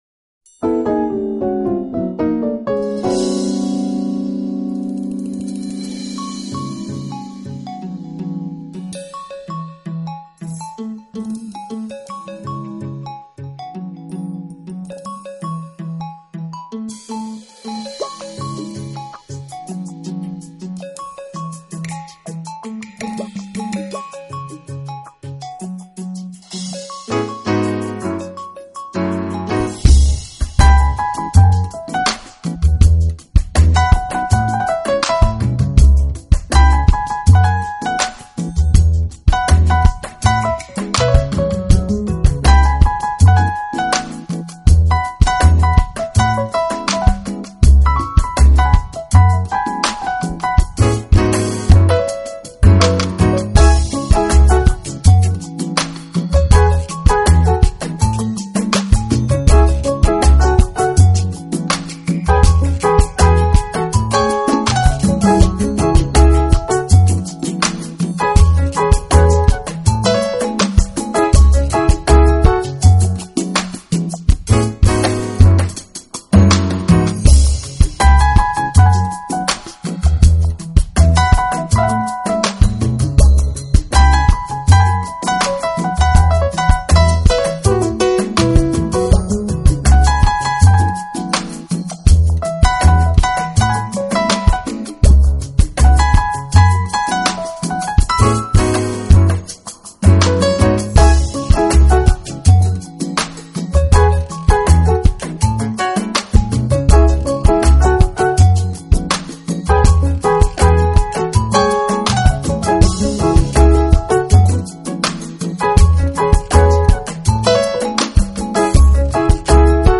风格：Smooth Jazz, Crossover Jazz